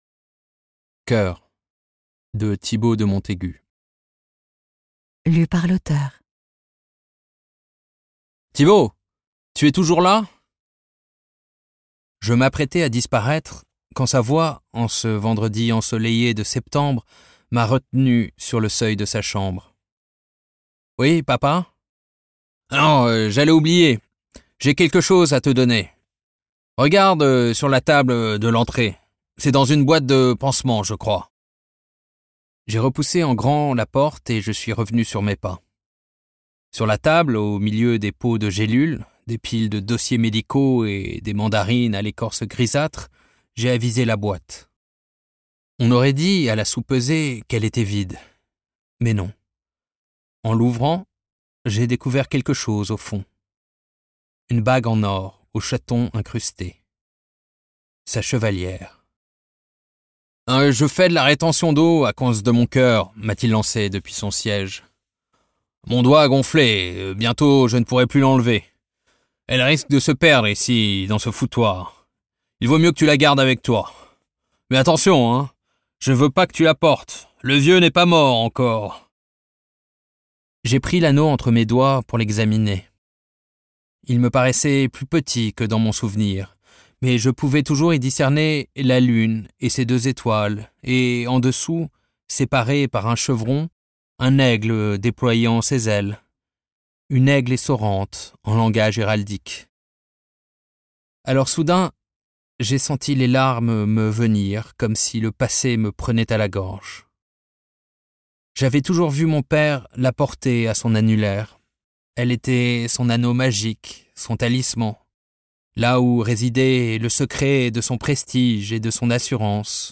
Diffusion distribution ebook et livre audio - Catalogue livres numériques
La course contre la montre qu'il engage alors pour remonter le passé se mue en une enquête bouleversante où se succèdent personnages proustiens et veuves de guerre, amants flamboyants et épouses délaissées. Interprétation humaine Durée : 08H29 22 , 95 € Ce livre est accessible aux handicaps Voir les informations d'accessibilité